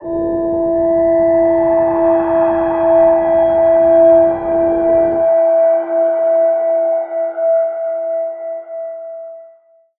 G_Crystal-E5-f.wav